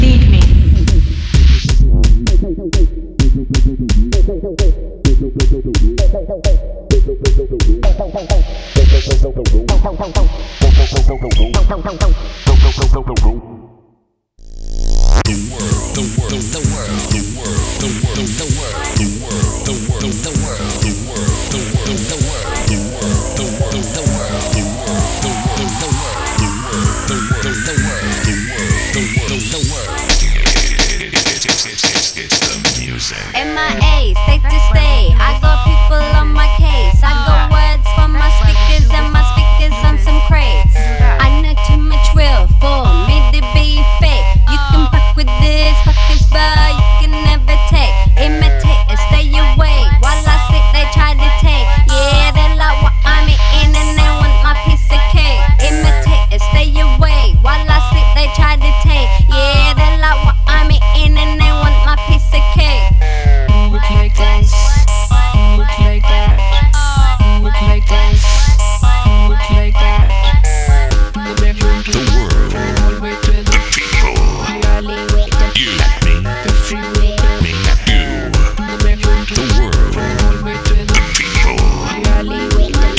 synth riff in tow